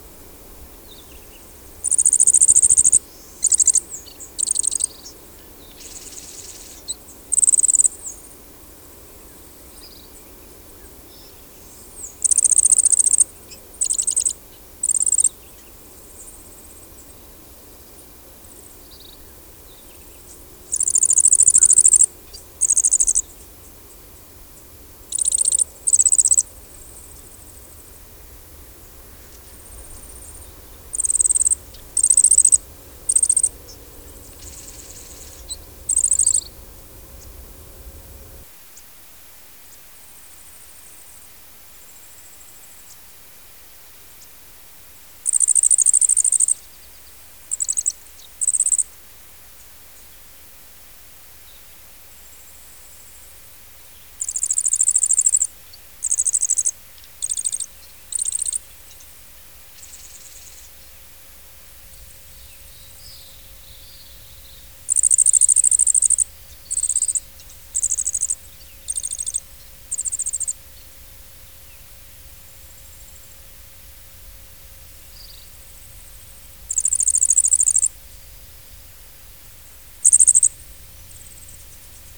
Yellow-faced Grassquit
Tiaris olivacea
VOZ: El canto típico es un trino débil y prolongado repetido en varios tonos. A veces el último trino es tan bajo que casi no se oye.
El canto puede ser tan débil que es difícil localizar el ave. El llamado se describe como un débil "trip".